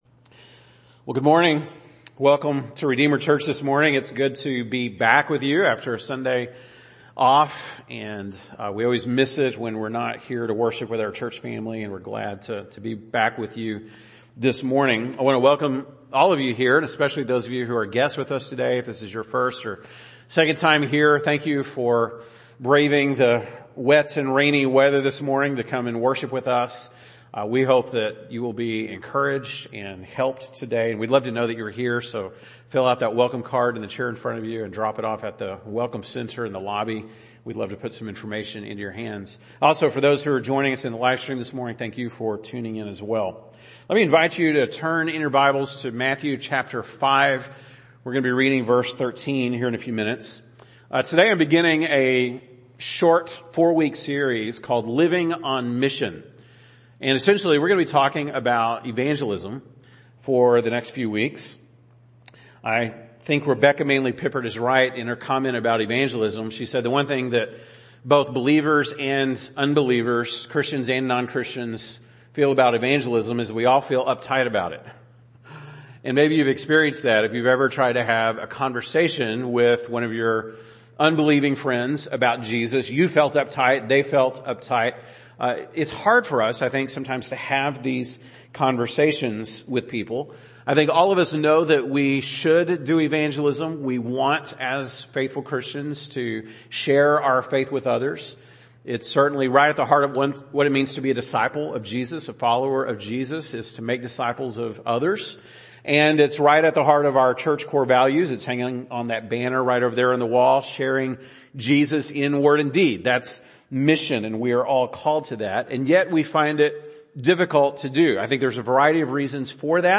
( Sunday Morning )